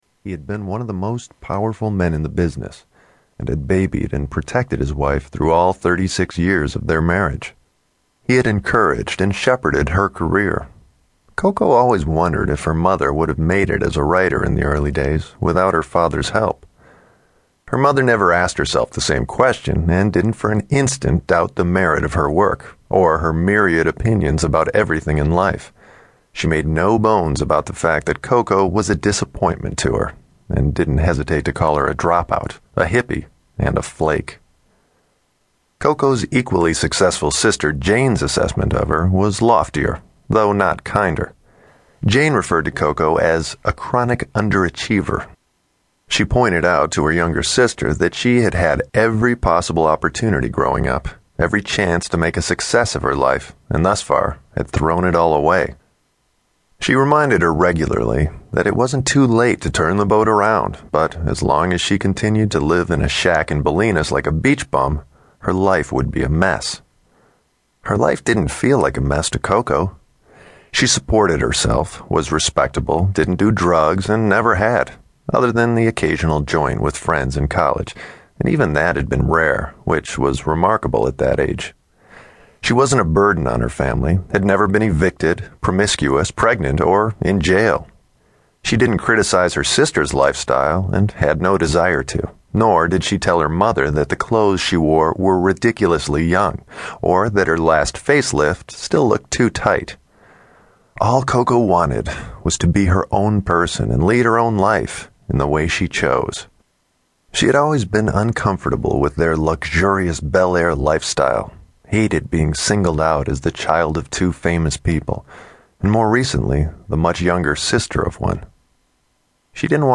Tags: Danielle Steel Audio Books Danielle Steel Danielle Steel Books Danielle Steel Audio Book Author